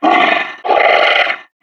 This is an audio clip from the game Team Fortress 2 .
Mercenary_Park_Yeti_statue_growl4.wav